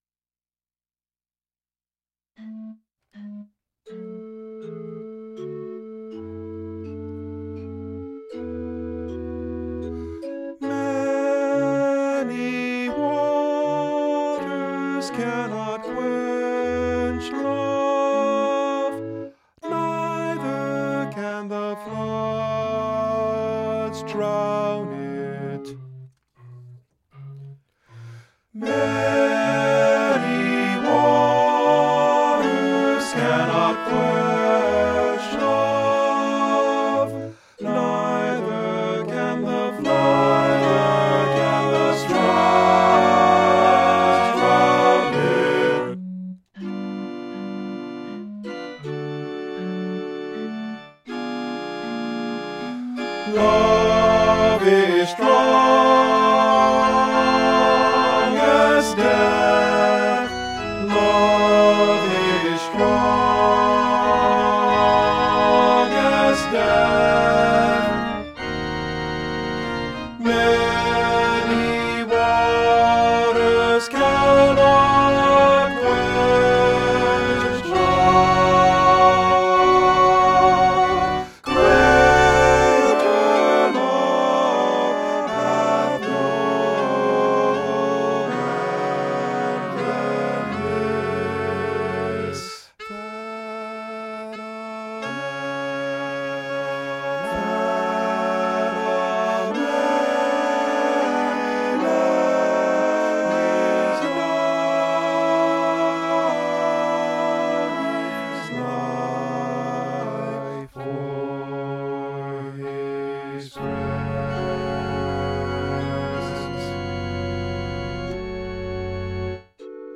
ALL   Instrumental | Downloadable